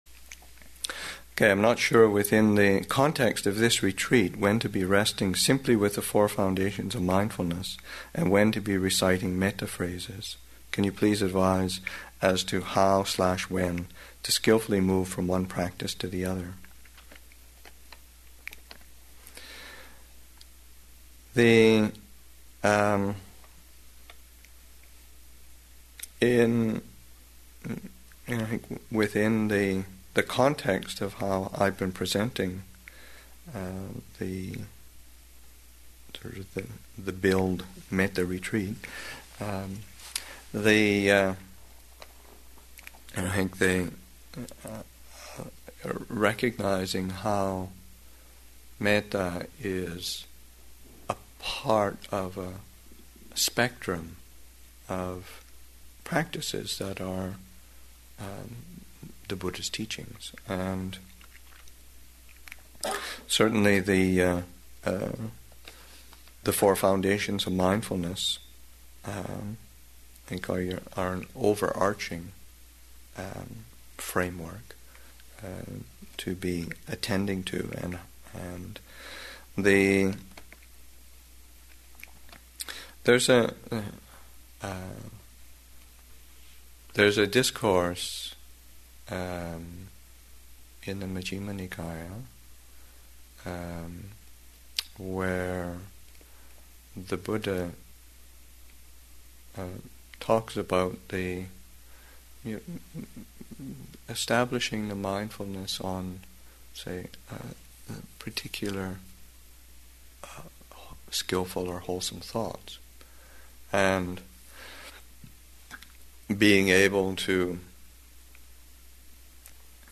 Metta Retreat, Session 3 – Sep. 11, 2008